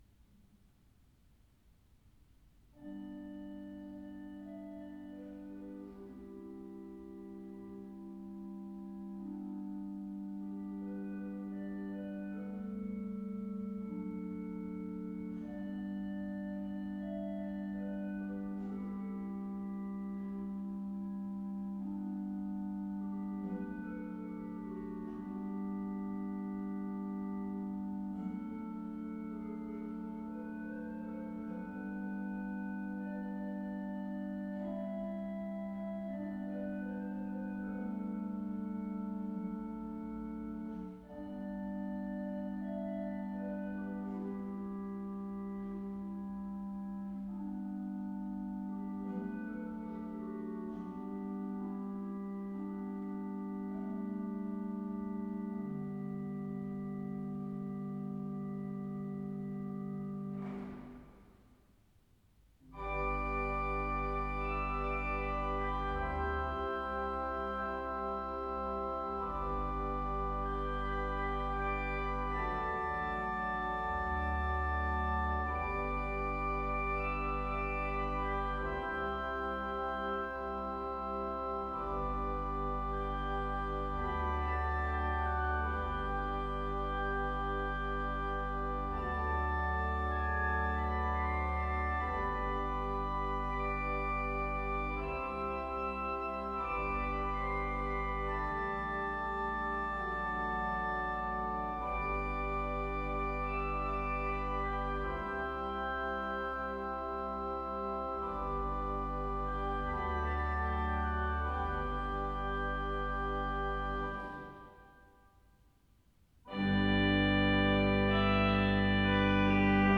Orgelet er byg i fransk/tysk romantisk stil fra 1800 tallet med Cavaillè-Coll som inspirasjon for rørstemmer og intonasjon.
Fanfare Opptakene jeg gjorde under besøket i Levanger kirke.
Levanger kirke   ZOOM H4n Pro 05.09.2022